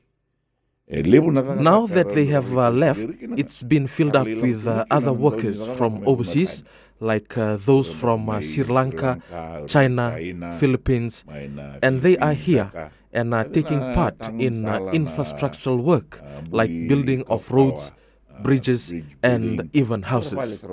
Speaking during Radio Fiji One’s Na Noda Paraiminisita program, Rabuka highlights the challenges faced by graduates who struggle to secure jobs aligned with their academic disciplines.